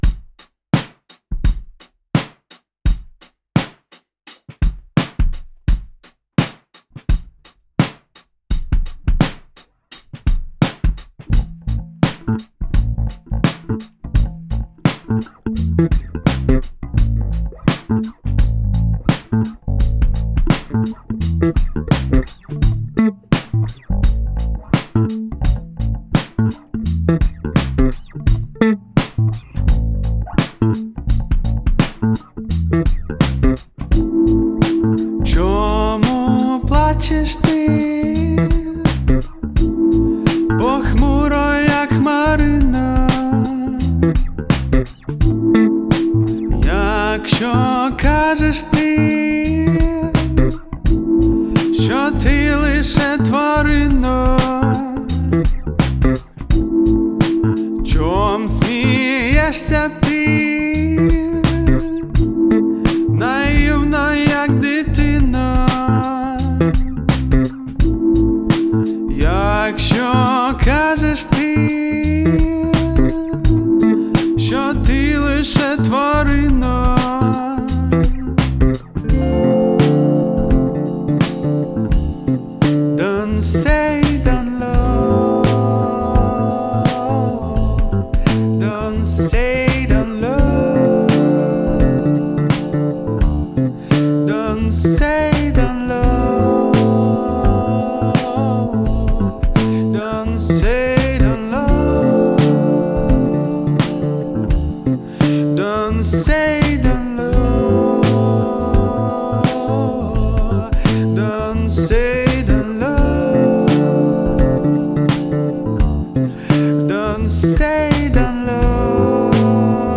• Жанр: Rock, Indie